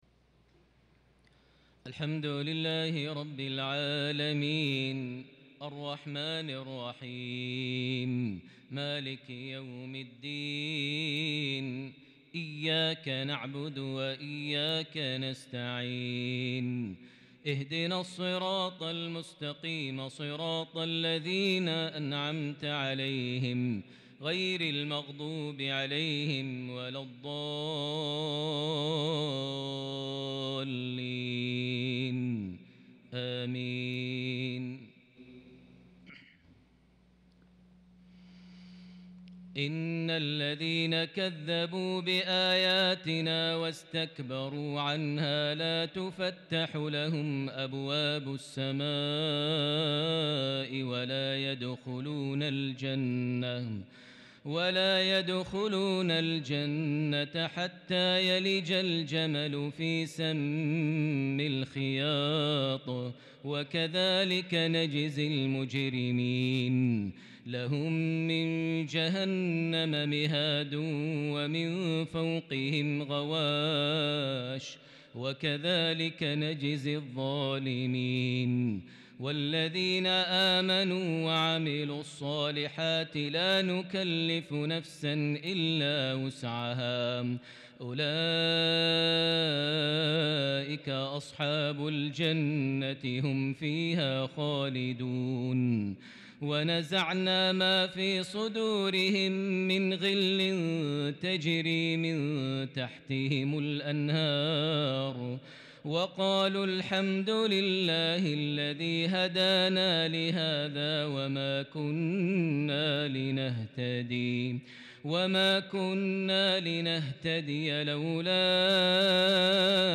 lsha 6-9-2021 prayer from Surah Al-Araf 40-53 > 1443 H > Prayers - Maher Almuaiqly Recitations